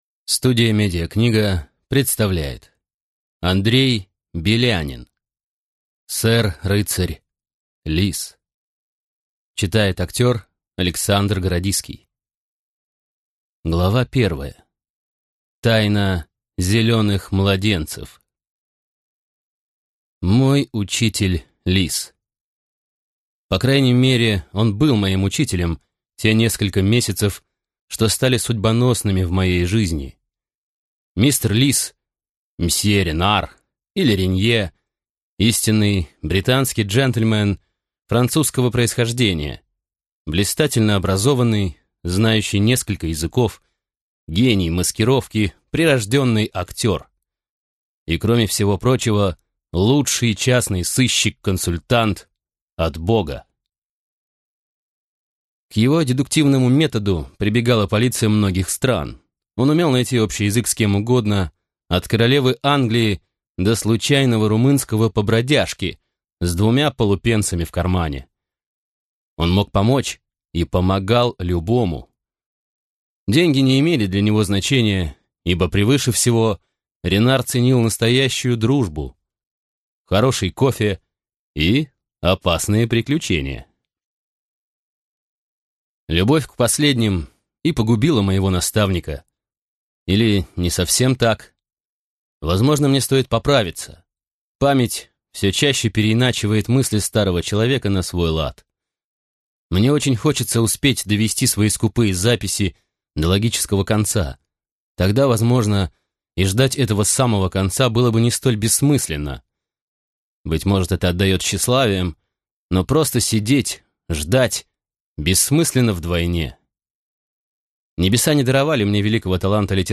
Аудиокнига Сэр рыцарь Лис | Библиотека аудиокниг